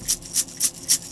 R - Foley 55.wav